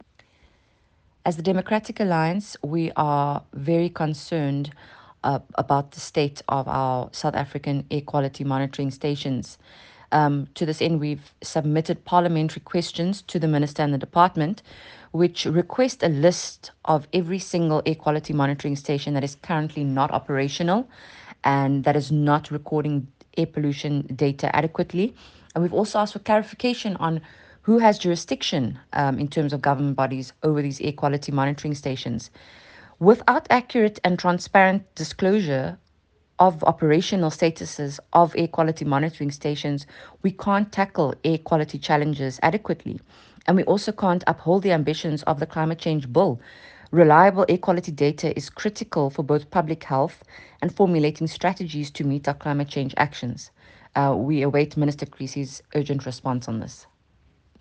soundbite by Hannah Shameema Winkler MP